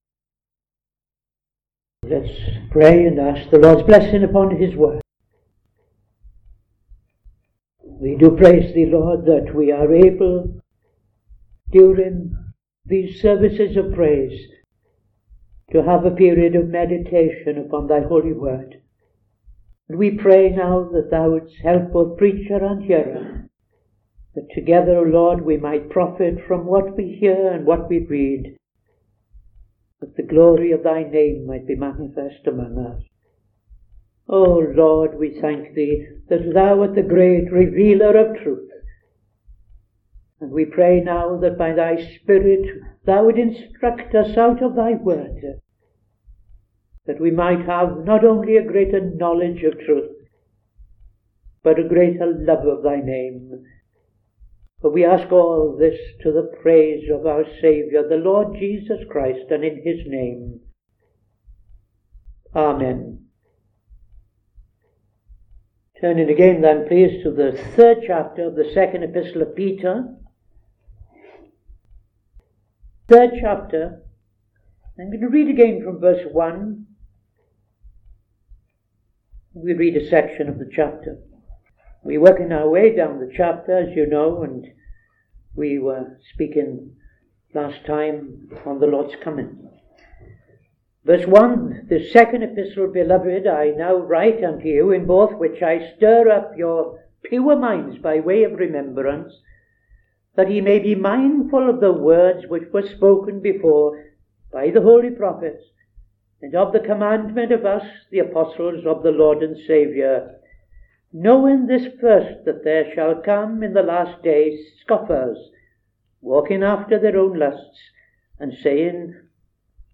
Opening Prayer and Reading II Peter 3:1-7